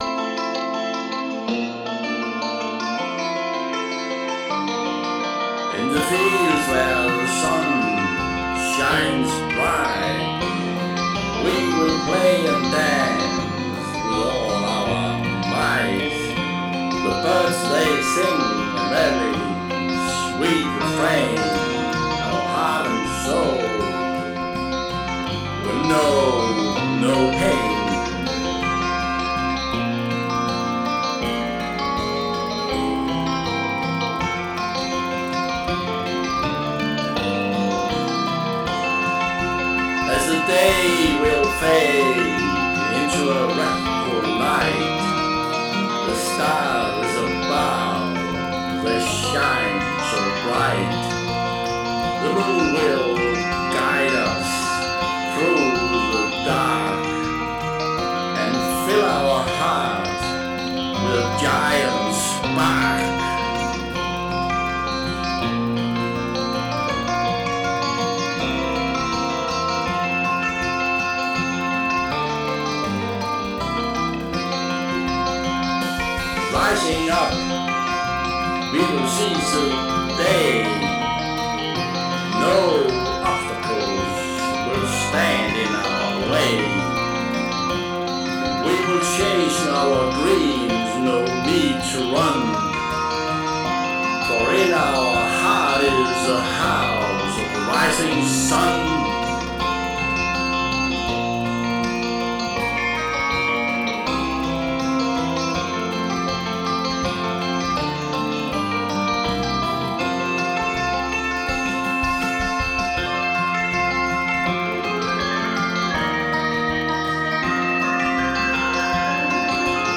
Electric Bass, Drums, Organ, 12 String Guitar, Vocal.
Genre: Folk Rock